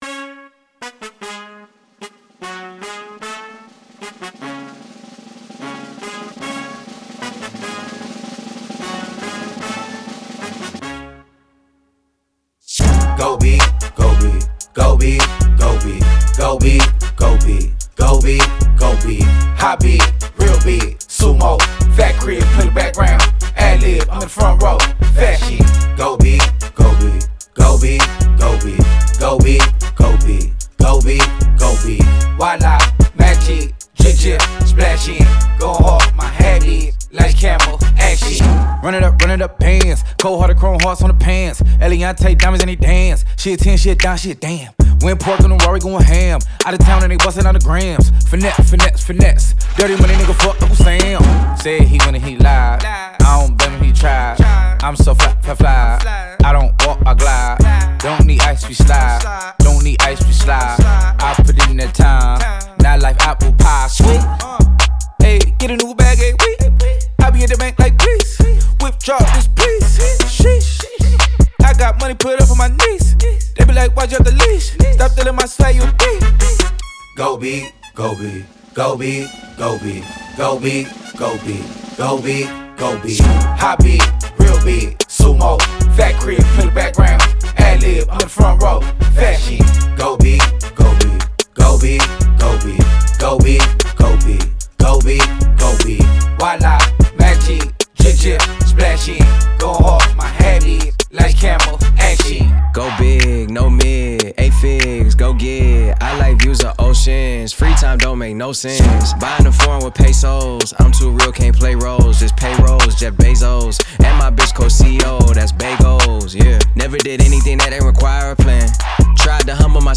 это энергичная композиция в жанре хип-хоп